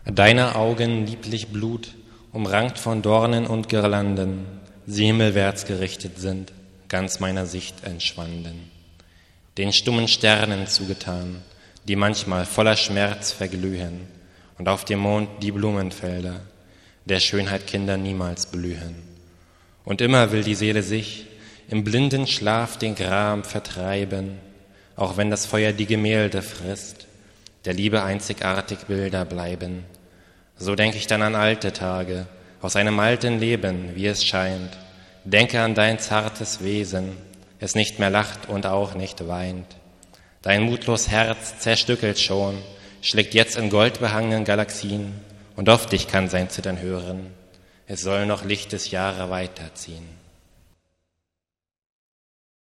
Das nur als Audiodatei hörbare Liebesgedicht beschreibt das Erkalten der Liebe. [Das Gedicht liegt nur als Gedichtvortrag vor.]
(Rezitation)